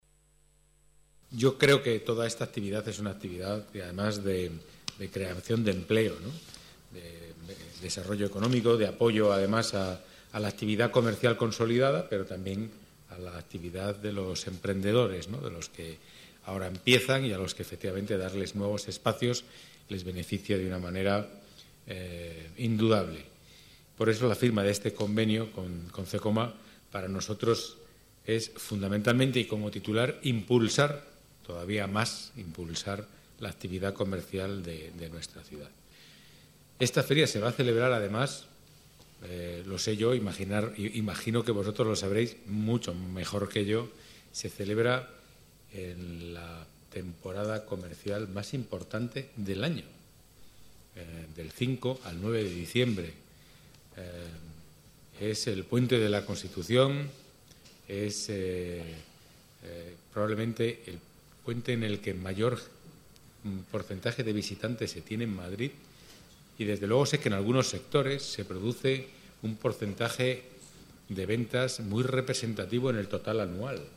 Nueva ventana:Declaraciones de Pedro Calvo, delegado de Economía y Empleo